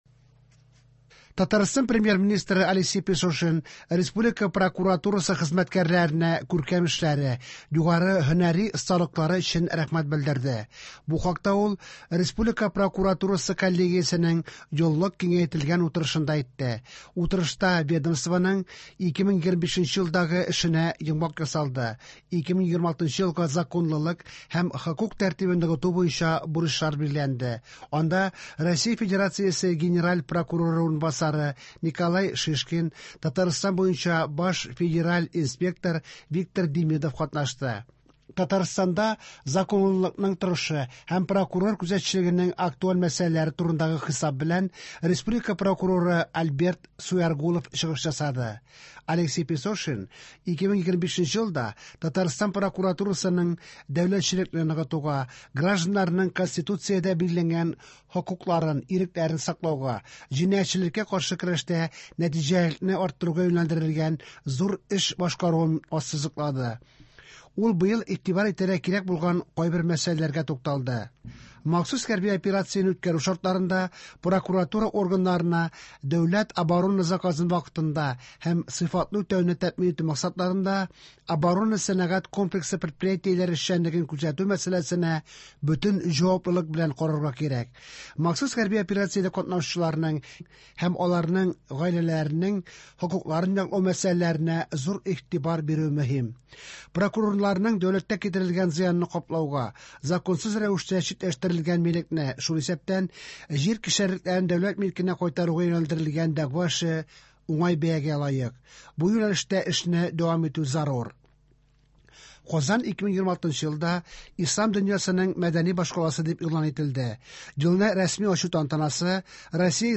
Иртәнге чыгарылыш.